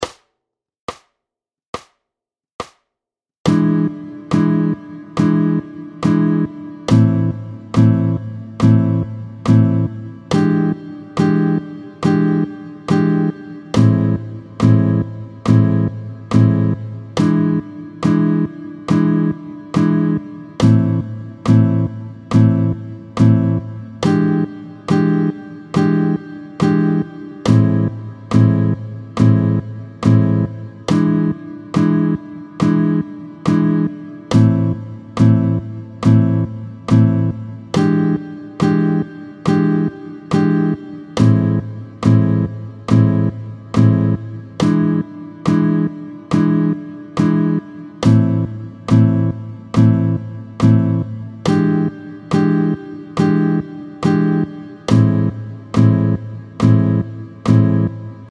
24-08 Le V7 / I mineur, tempo 70